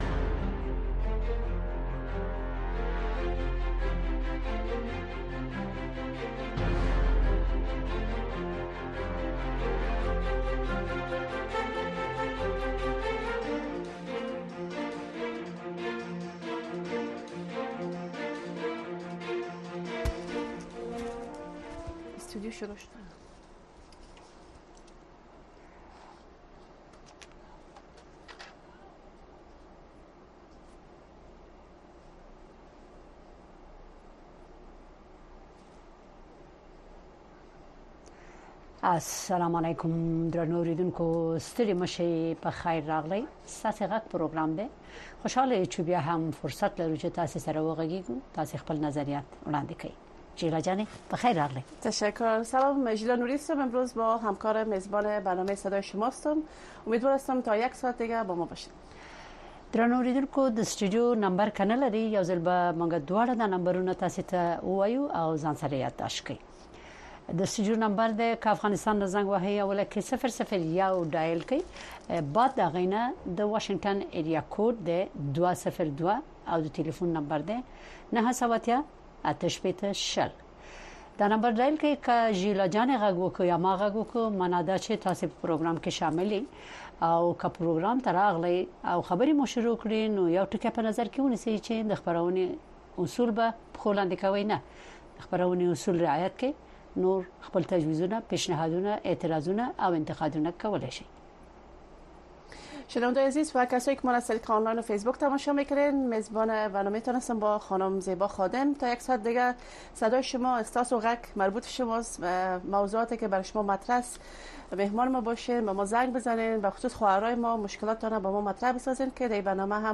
این برنامه به گونۀ زنده از ساعت ۹:۳۰ تا ۱۰:۳۰ شب به وقت افغانستان نشر می‌شود.